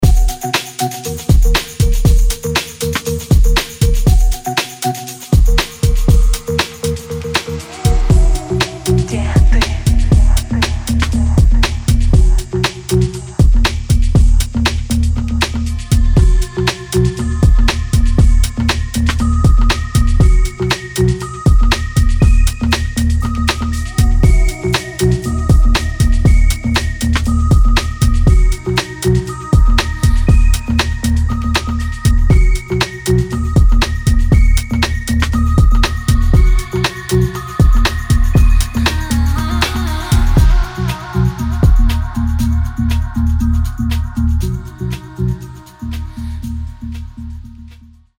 атмосферные
Electronic
low bass
house
future garage
электронный проект, создающий атмосферную музыку.